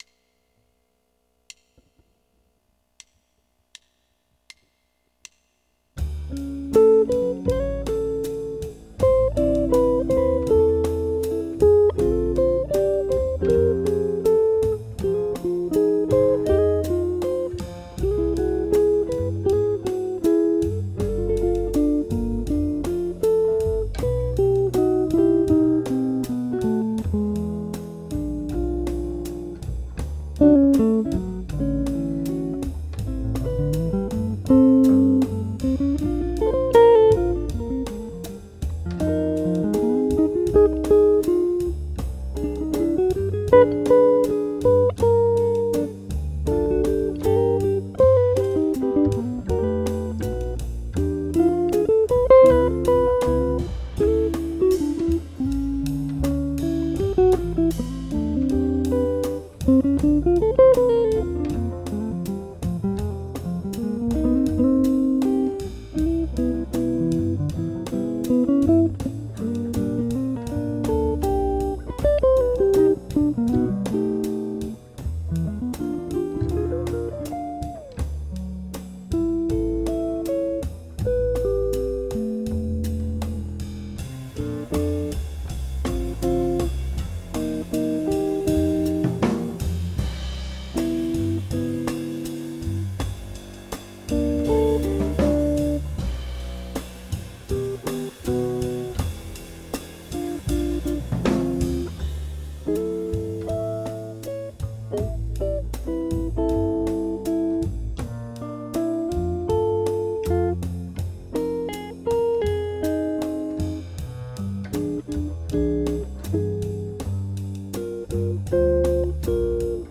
Maj7#5 - Guitar Lesson